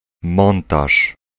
Ääntäminen
US : IPA : [ə.ˈsɛm.bli] UK : IPA : /əˈsɛmb.lɪi/